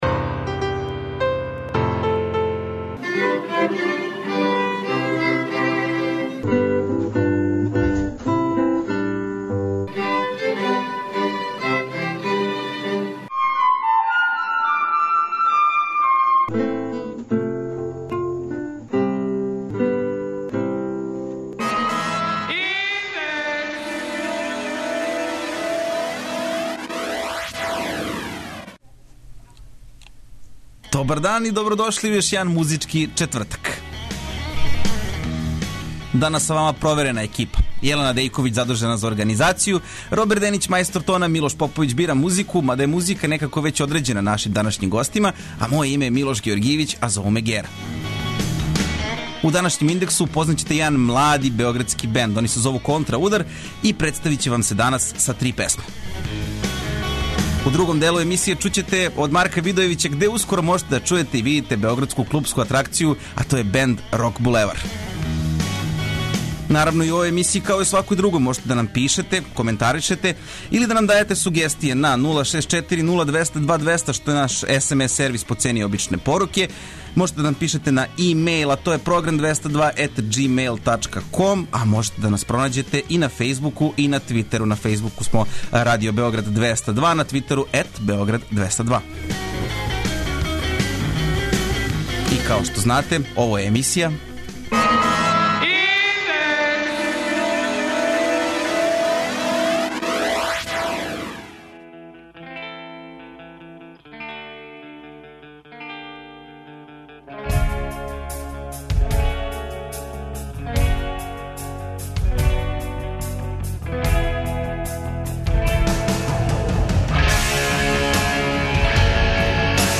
Четвртак у Индексу је као и обично, музички четвртак!
преузми : 17.66 MB Индекс Autor: Београд 202 ''Индекс'' је динамична студентска емисија коју реализују најмлађи новинари Двестадвојке.